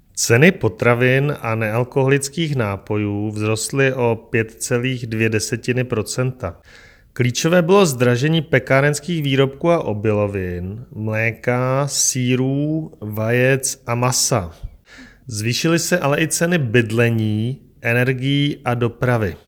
Vyjádření předsedy ČSÚ Marka Rojíčka, soubor ve formátu MP3, 763.31 kB